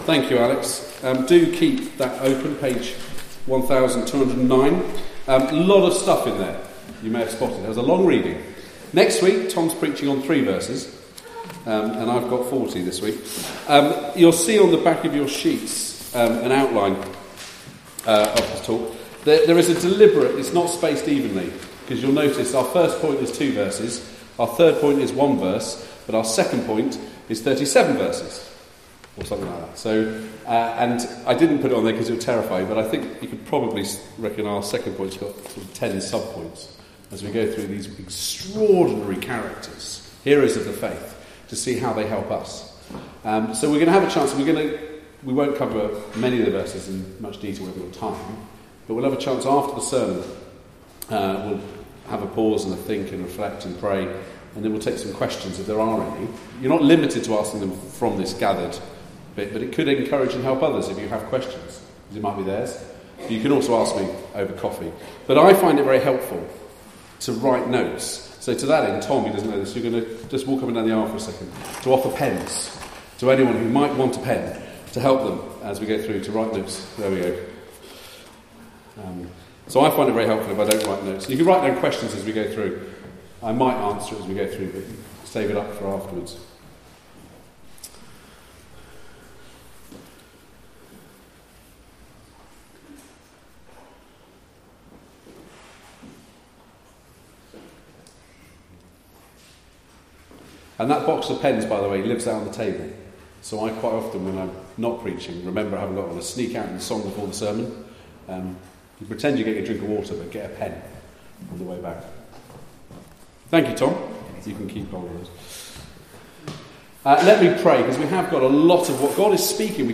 1-40 Service Type: Weekly Service at 4pm Bible Text